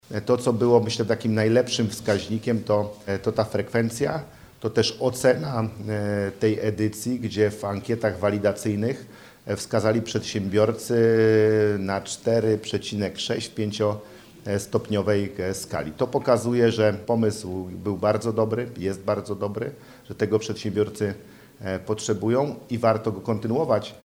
– Uczestnicy ocenili projekt bardzo wysoko – dodaje Paweł Gancarz.